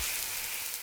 acid-mid.ogg